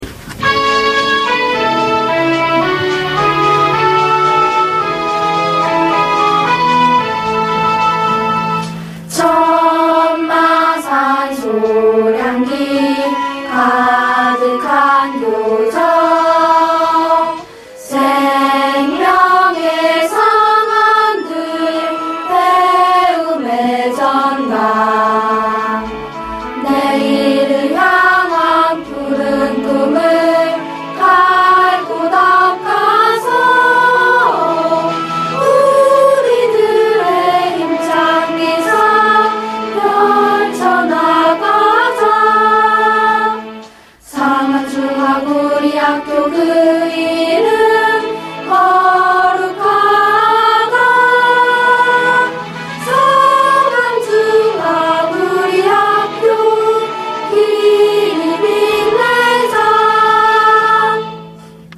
상안중학교 교가 음원 :울산교육디지털박물관
상안중학교의 교가 음원으로 이재천 작사, 김경희 작곡이다.